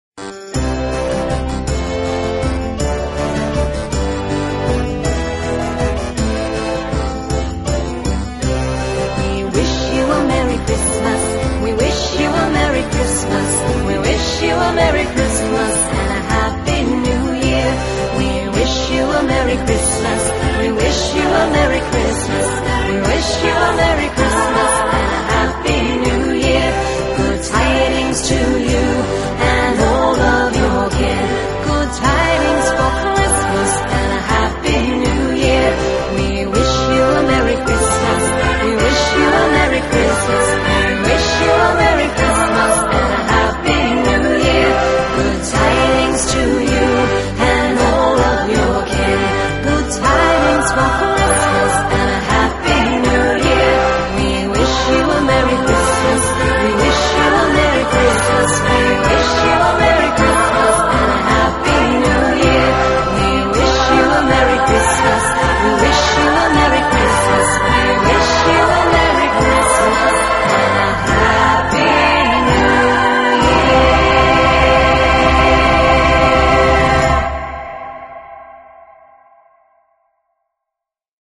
专辑类型：Folk/Rock